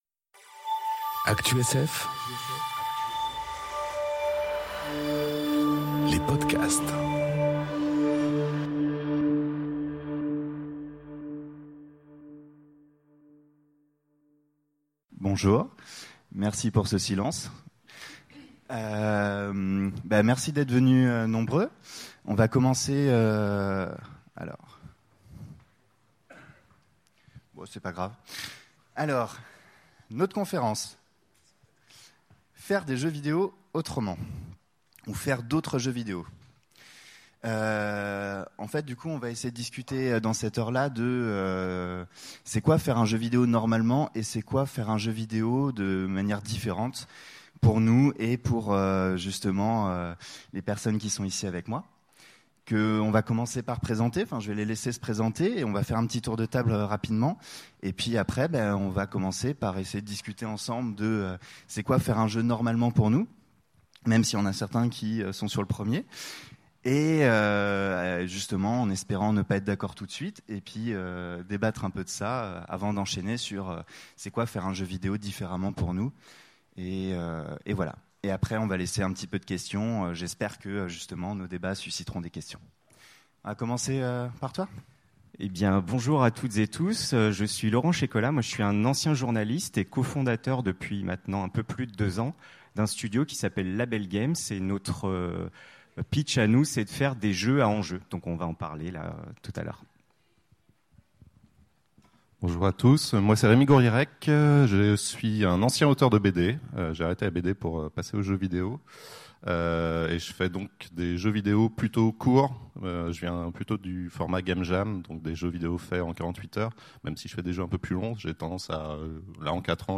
Conférence Faire d’autres jeux vidéo enregistrée aux Utopiales 2018